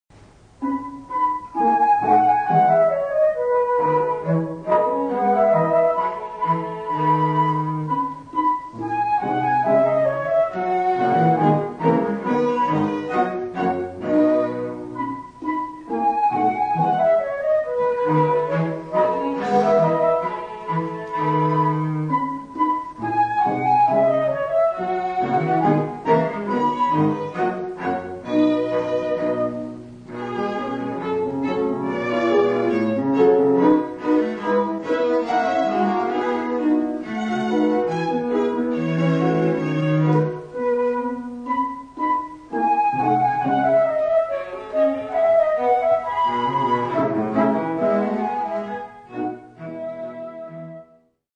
Muchas grabaciones que aquí se ofrecen se registraron en presentaciones en vivo durante las décadas de 1950, 1960 y 1970.
Divertimento para flauta, violín, violoncello y piano (1959).